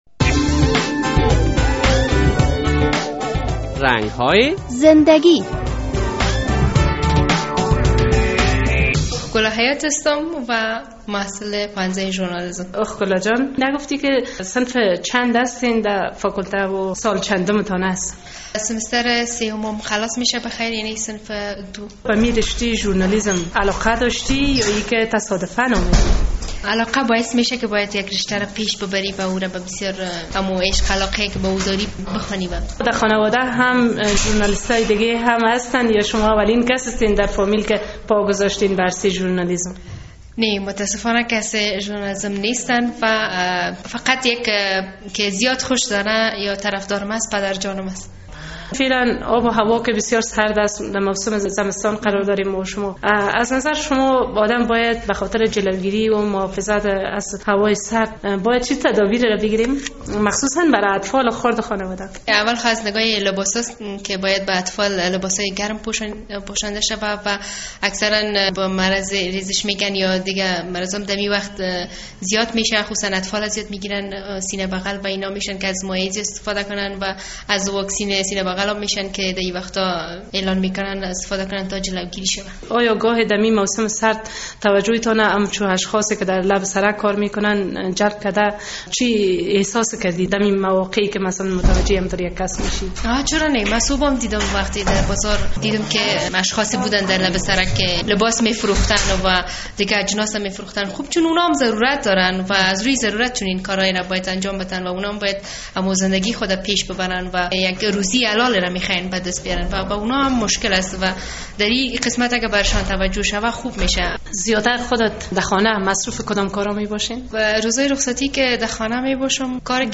در این برنامهء رنگ های زندگی خبرنگار رادیو آزادی با یک محصل پوهنځی ژورنالیزم صحبت کرده است.